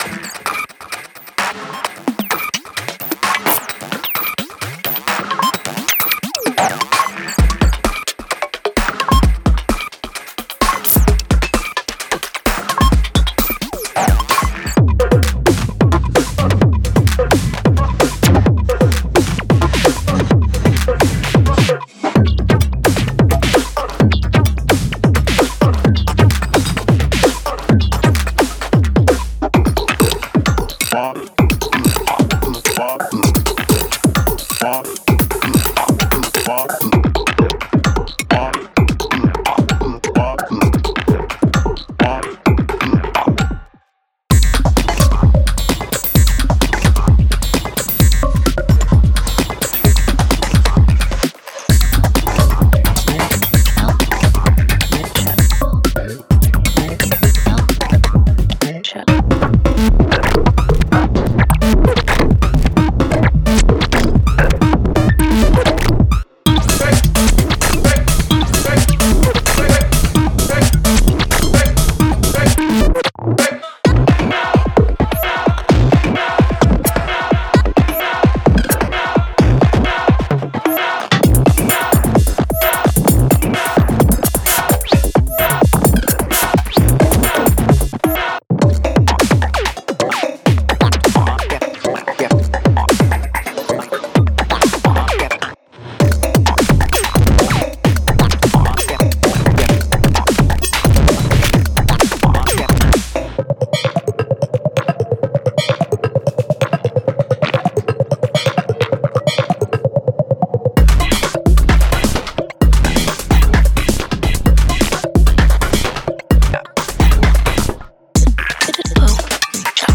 Genre:IDM
本パックは無調性の世界へ真正面から踏み込み、リズム、質感、動きを作曲の核として探求します。
110 Percussion Loops